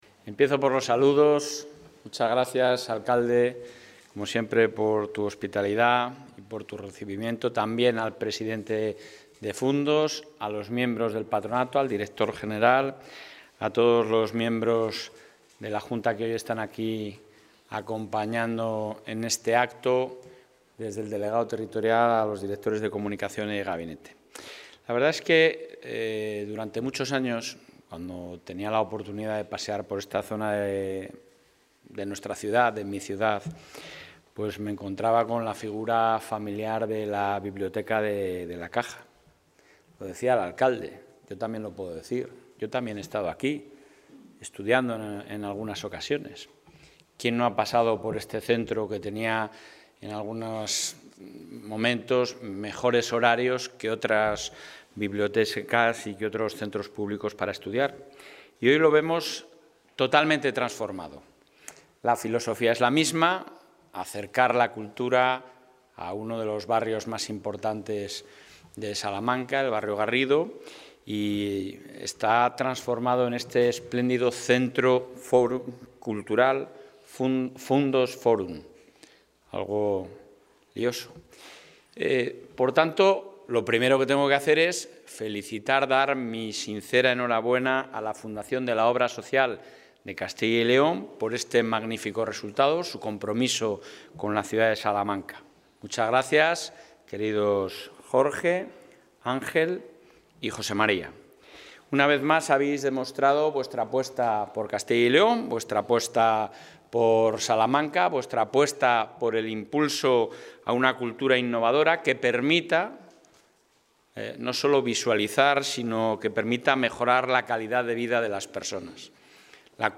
Intervención del presidente de la Junta.
En el acto inaugural del Centro Cultural Fundos Fórum de la Fundación Obra Social de Castilla y León, el presidente del Gobierno autonómico, Alfonso Fernández Mañueco, ha destacado la importancia de las iniciativas pública y social, a través de sus agentes, para preservar e impulsar la cultura; algo que, a su juicio, resulta fundamental para garantizar la calidad democrática de una sociedad.